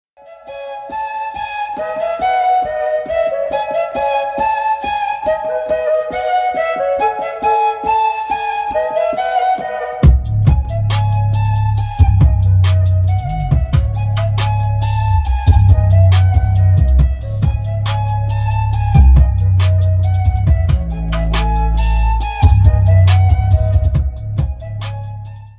Хип-хоп
без слов
качающие
Bass
Флейта
Интересный бит под звуки флейты.